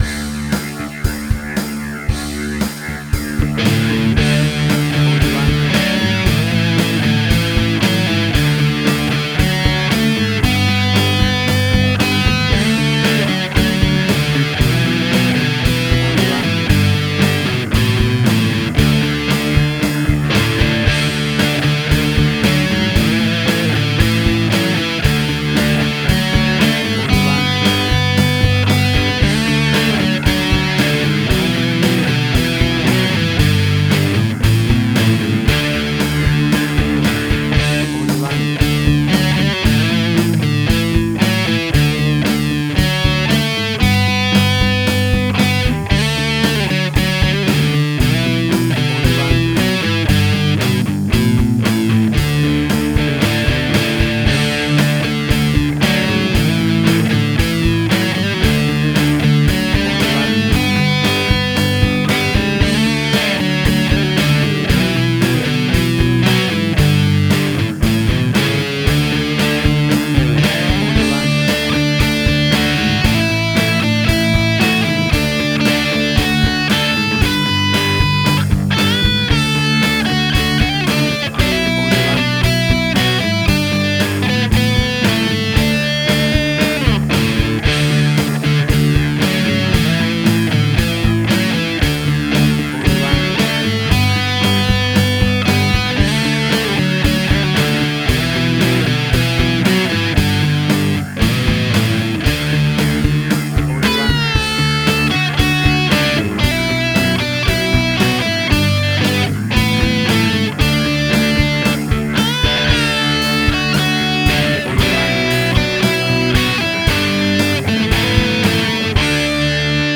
rocked up version
Tempo (BPM): 115